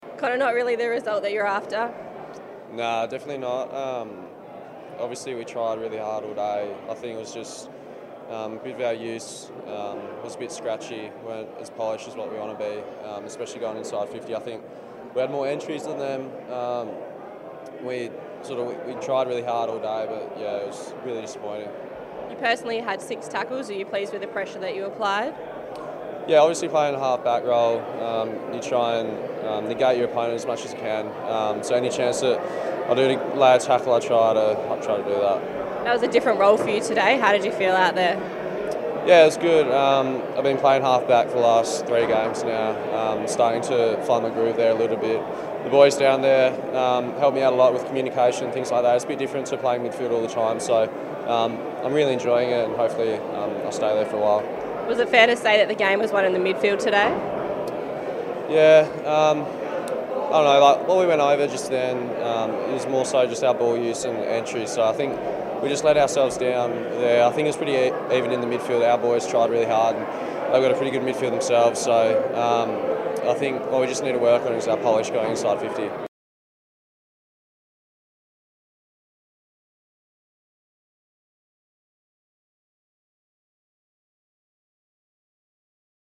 Connor Blakely chats to Docker TV after Freo's clash against the Eagles.